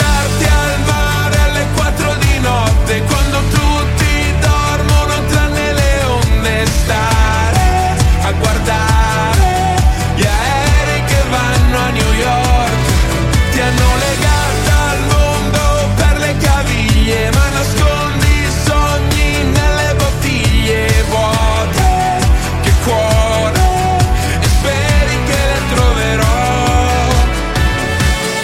Genere: pop,disco,trap,rap,dance,hit